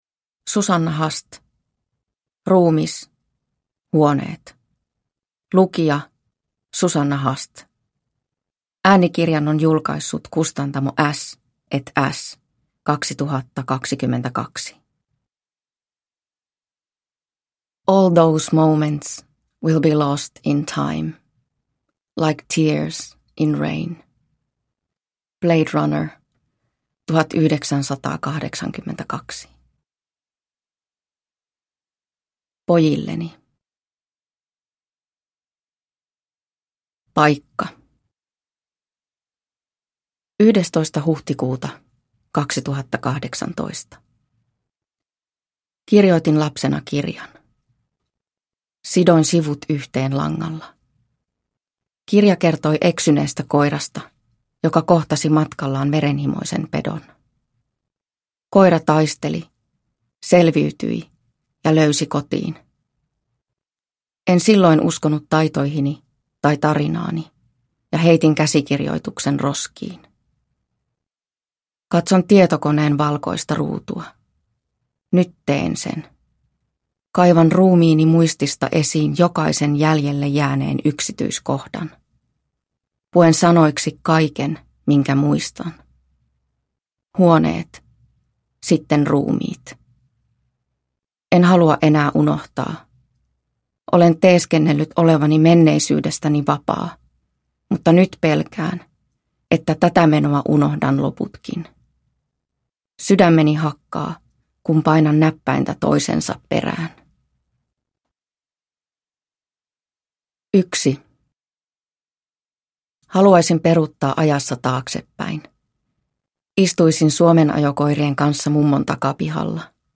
Ruumis/huoneet – Ljudbok – Laddas ner